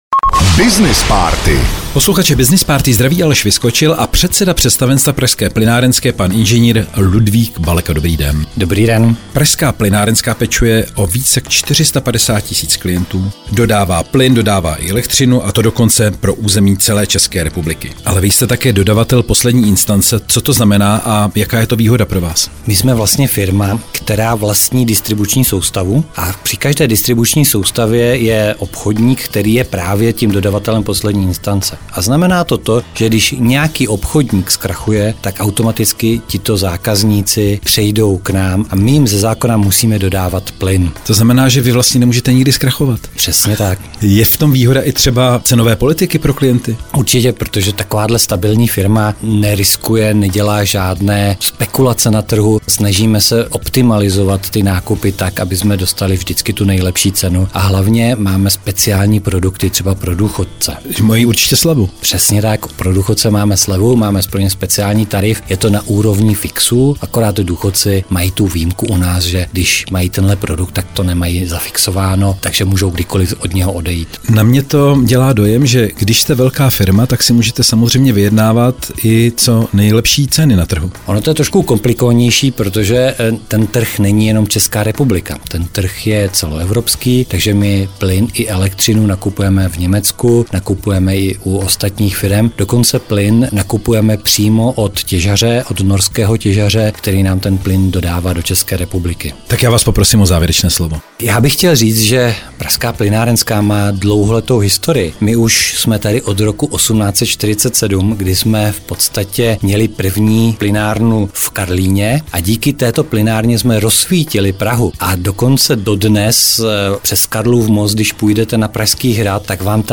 Veškerá témata rozhovorů na Frekvenci 1 jsou dostupná na uvedených odkazech:
Rozhovor 4
rozhovor_04.mp3